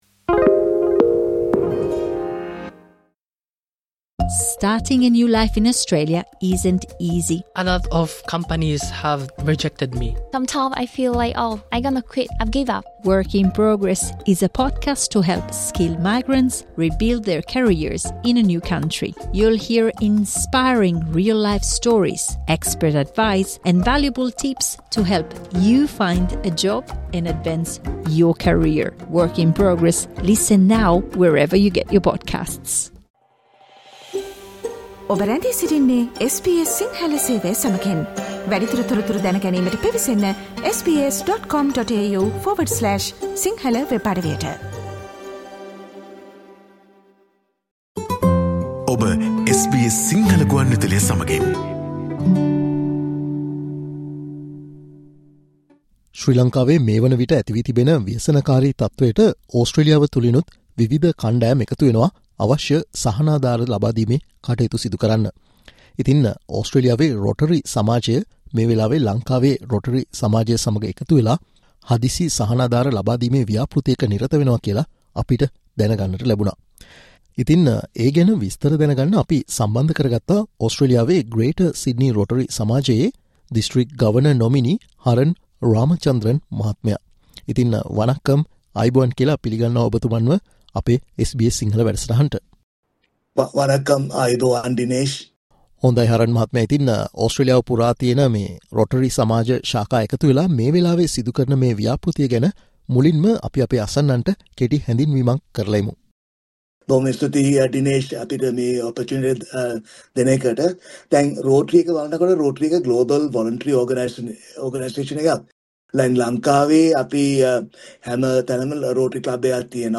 ඉතින් පවතින තත්වය යටතේ ඔවුන් සහන ස්ලසීමේ ව්‍යාපෘති සිදුකරන ආකාරය ගැන තොරතුරු මේ සාකච්චාවෙන් අපි ඔබ වෙත ගෙන එනවා.